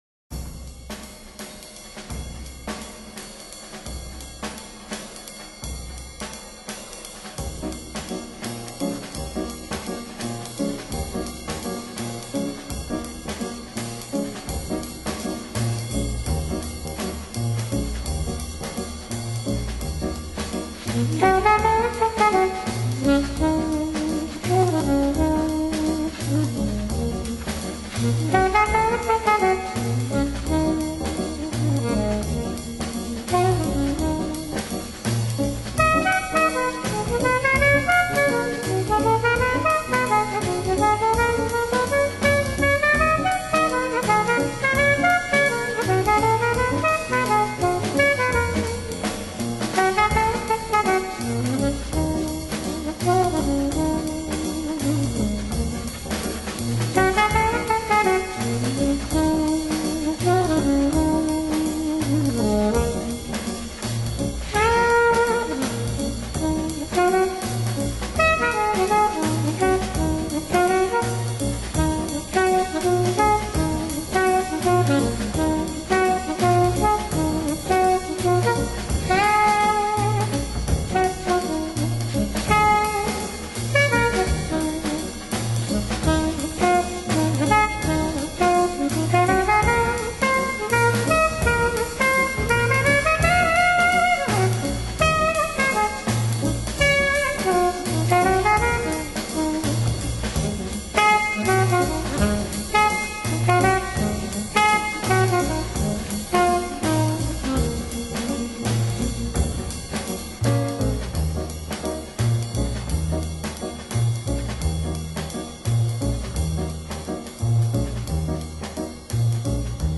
Genre: Cool, West Coast Jazz